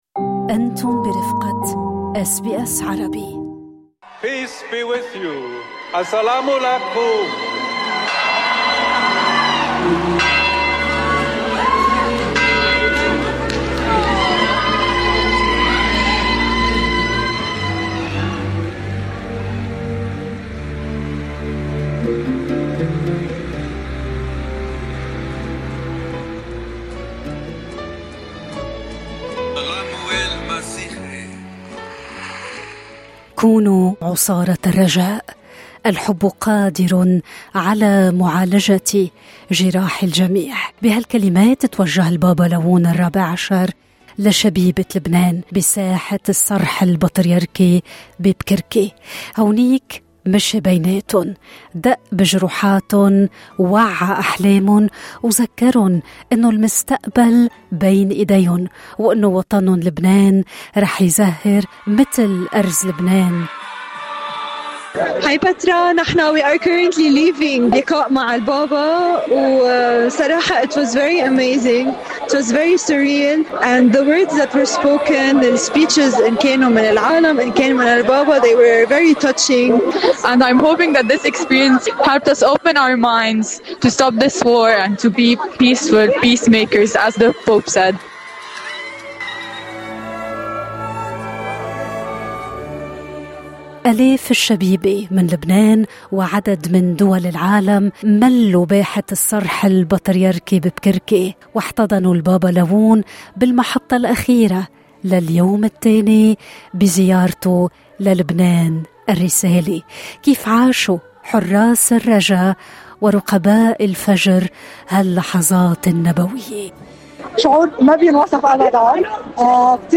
قراءة للثمار الروحية، شهادات ودموع من قلب الحدث في "رحيل أصعب من الوصول" في هذا التقرير الصوتي.